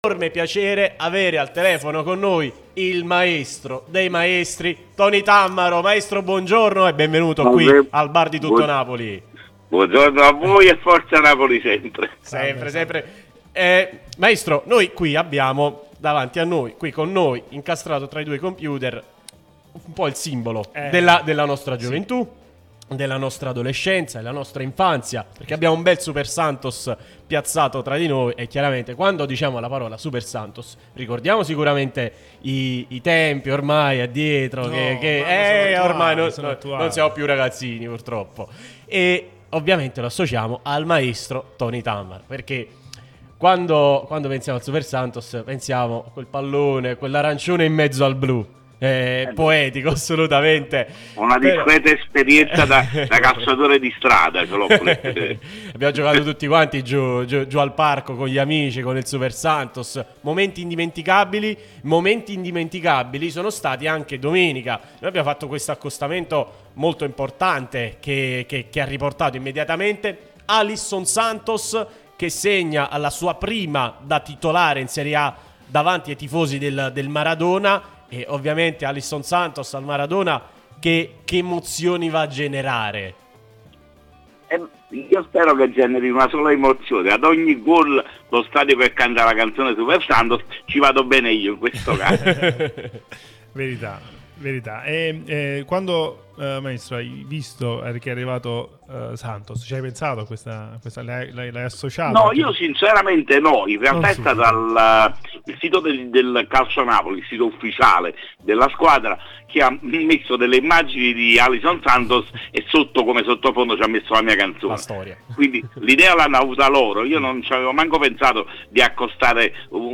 Tony Tammaro , cantautore e comico, noto anche per la canzone 'Super Santos' tornata in auge dopo la rete di Alisson contro la Roma, è intervenuto su Radio Tutto Napoli , prima radio tematica sul Napoli